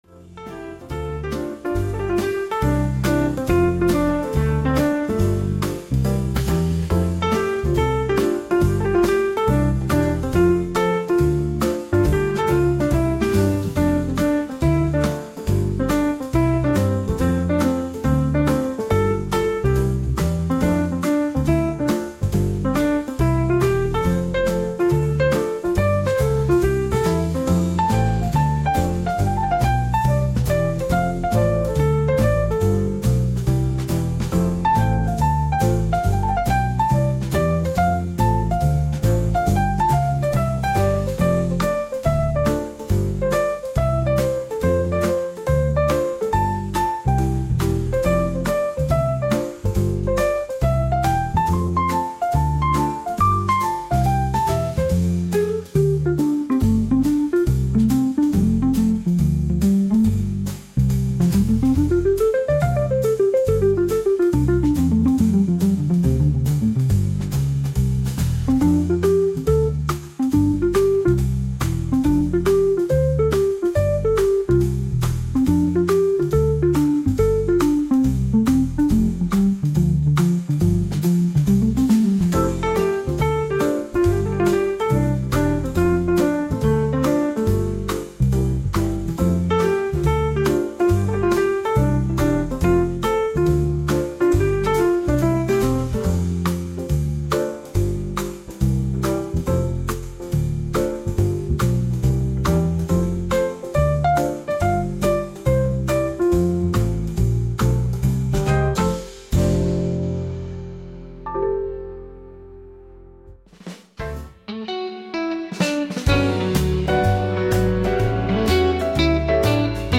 こちらがレコード音質加工前の原音です